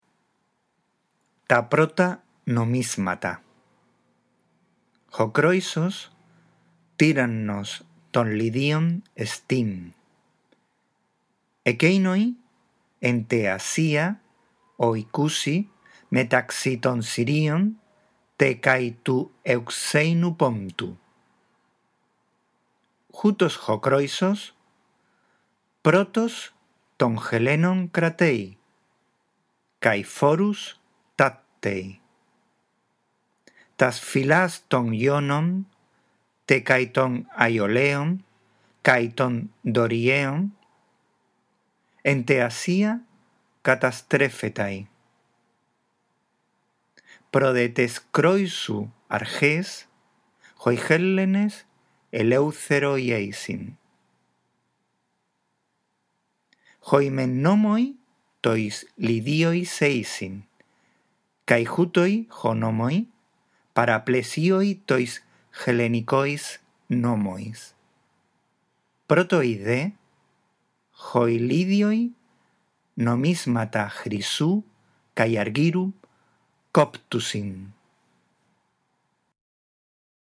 A.1. Lee, en primer lugar, despacio y en voz alta el texto.